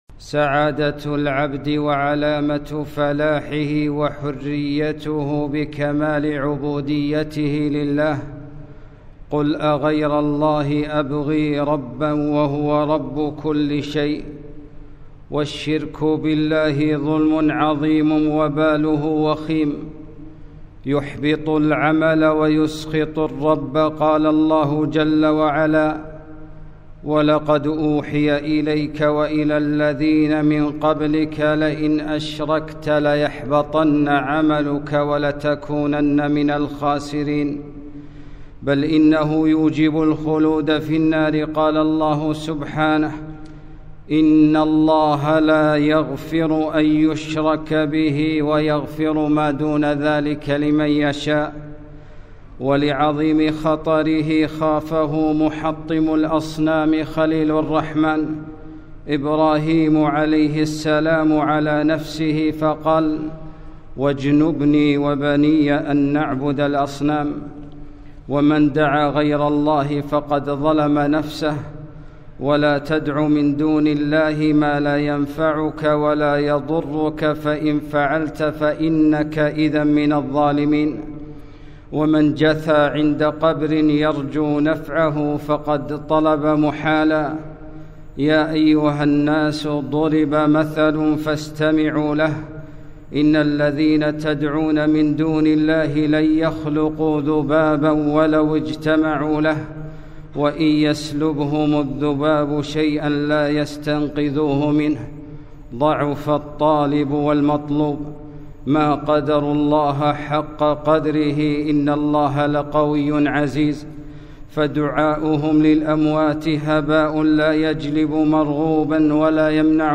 خطبة - دعاء أهل القبور